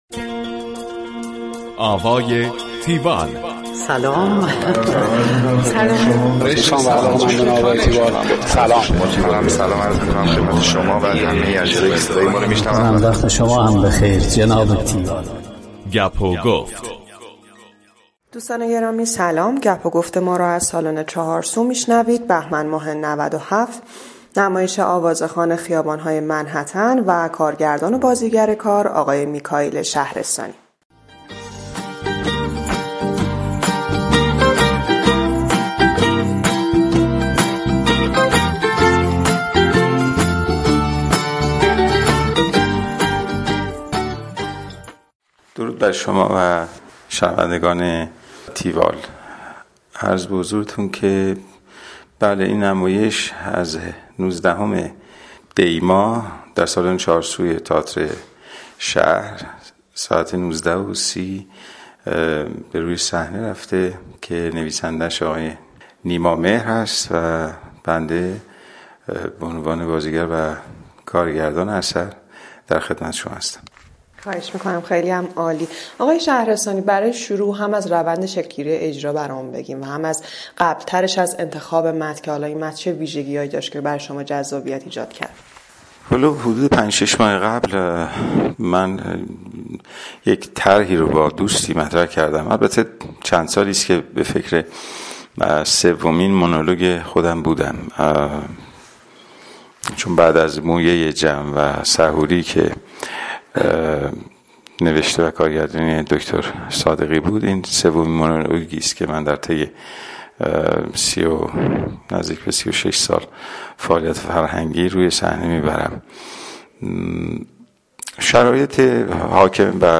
tiwall-interview-mikaielshahrestani.mp3